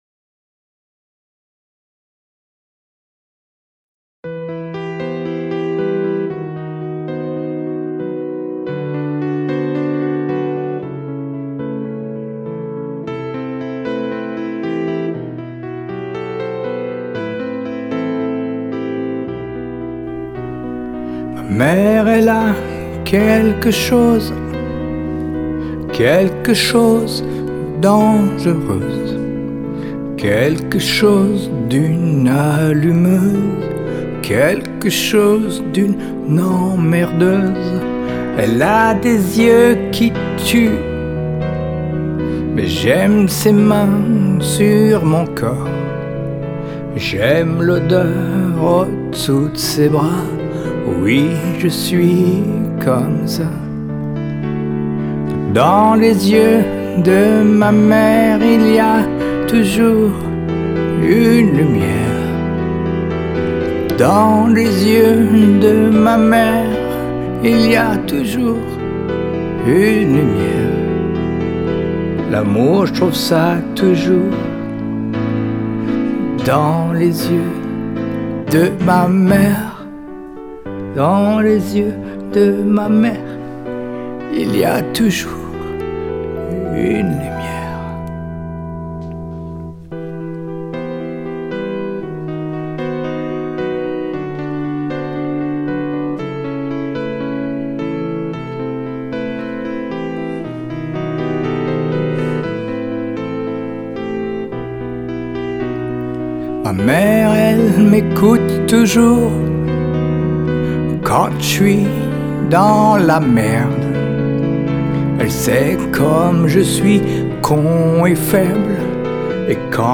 mêlant des influences jazz et blues